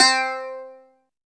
69 CLAV C4-L.wav